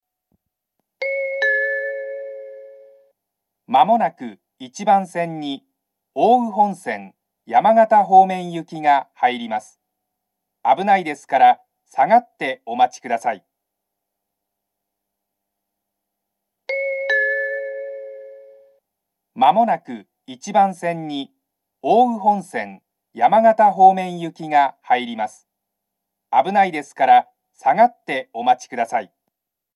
１番線上り接近放送